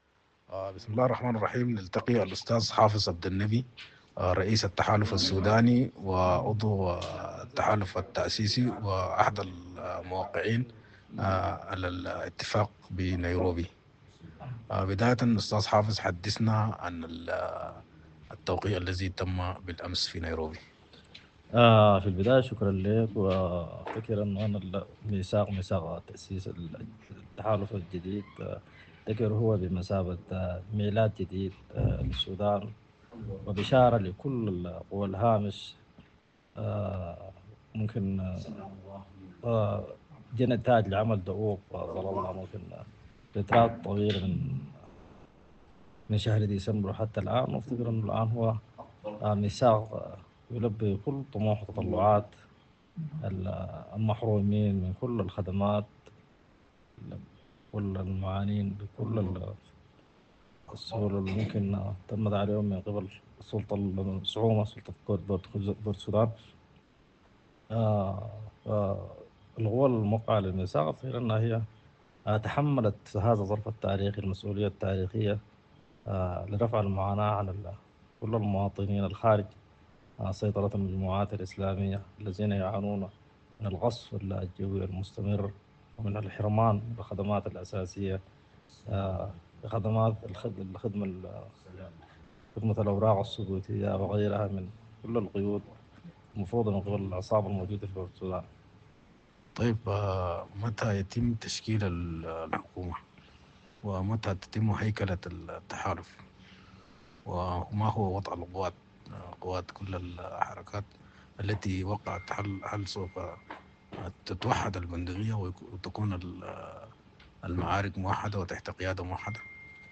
● على ضوء ذلك التقت “السودانية نيوز” بالأستاذ حافظ عبد النبي وزير الثروة الحيوانية السابق، واحد الموقعين على الميثاق التأسيسي، ليلقي الضوء على على الحدث التاريخي الهام
الحوار-كاااامل-.mp3